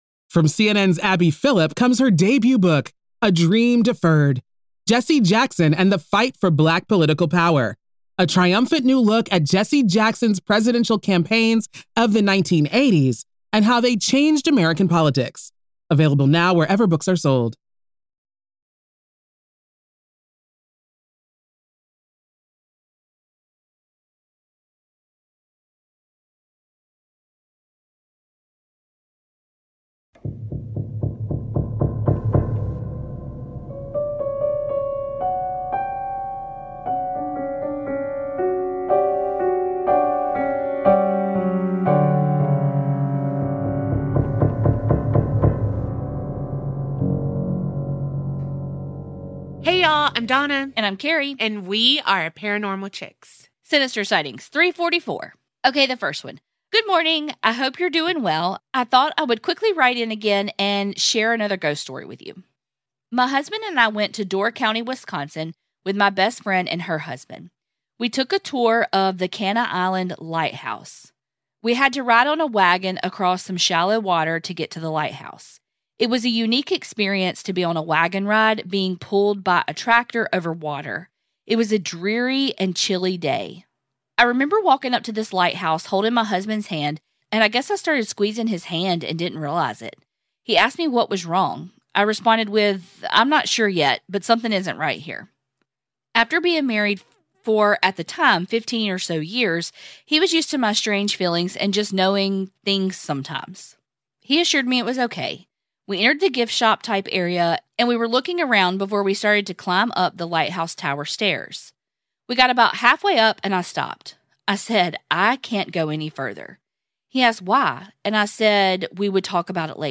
Sinister Sightings are your true crime and true paranormal stories. Every week we read out ones that you've sent in.